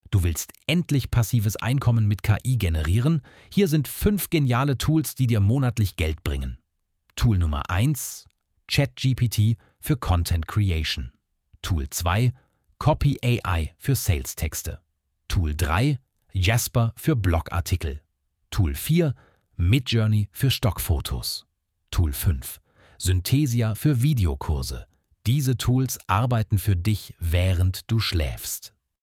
Automatisch generierte Videos mit deutscher KI-Stimme
• Stimme: Stefan (ElevenLabs German)
🎤ElevenLabs TTS: Stefan Voice (Deutsche Native Speaker)